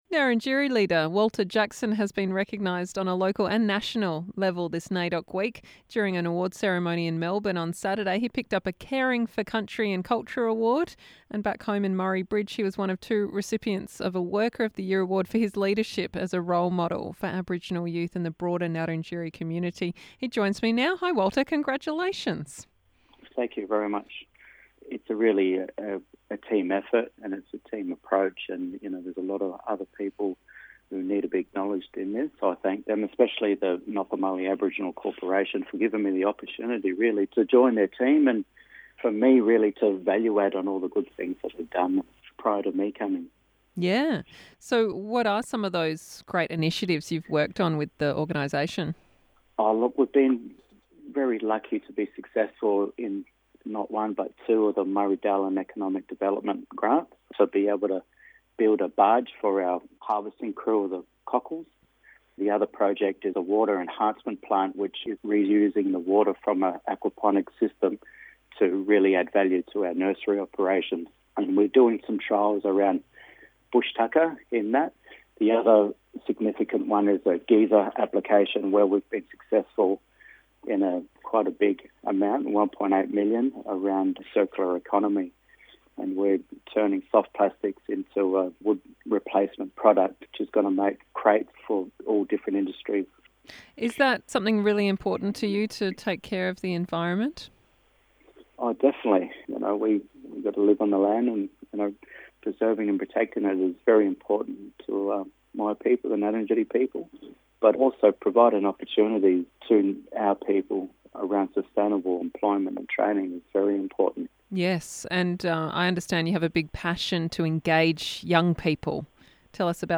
a chat